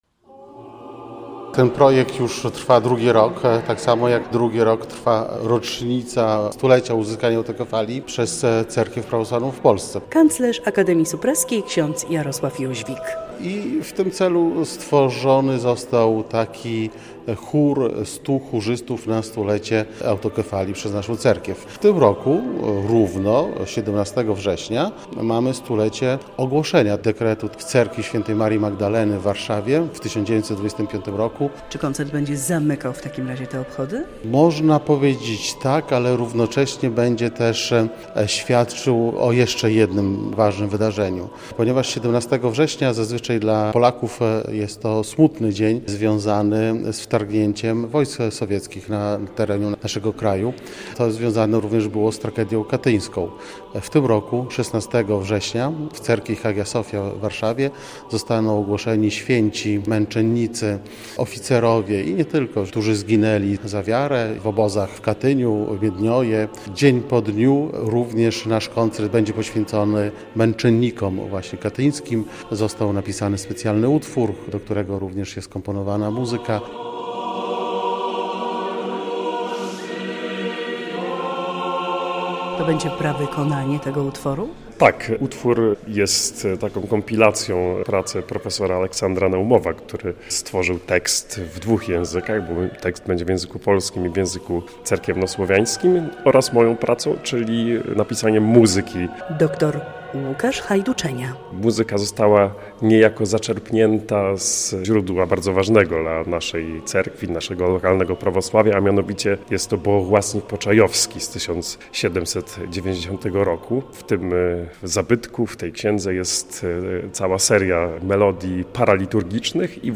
relacja
W programie znajdą się dawne monodyczne śpiewy Rzeczypospolitej, pieśni paraliturgiczne oraz muzyka kilku  kompozytorów. O szczegółach mówią organizatorzy.